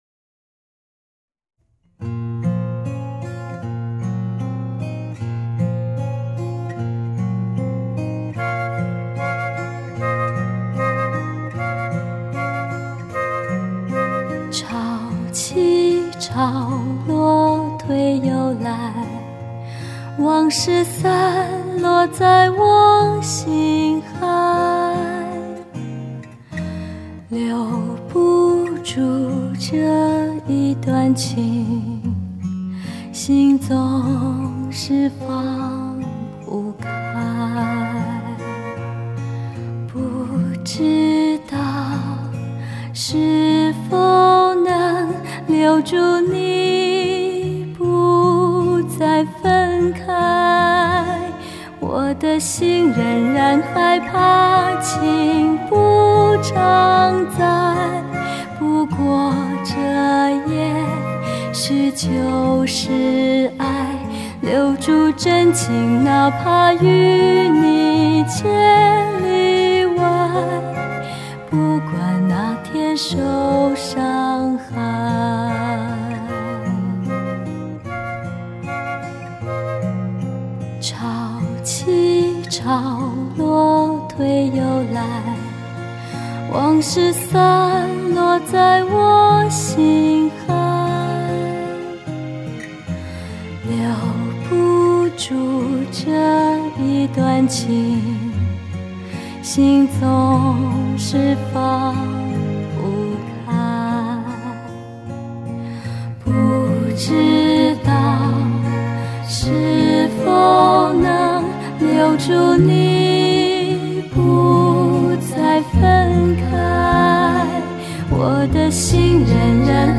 人声润厚通透 情感洋溢 质感层次感一一再现眼前出